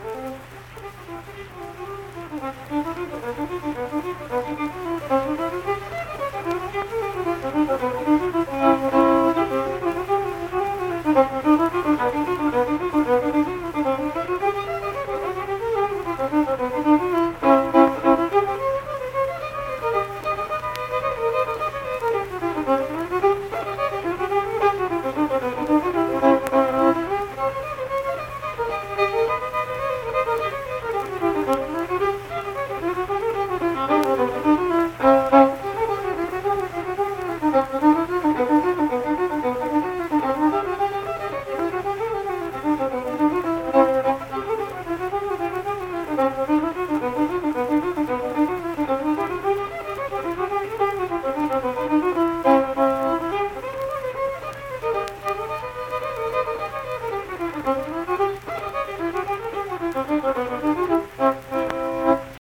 Cuckoo's Nest - West Virginia Folk Music | WVU Libraries
Unaccompanied vocal and fiddle music
Instrumental Music
Fiddle
Pleasants County (W. Va.), Saint Marys (W. Va.)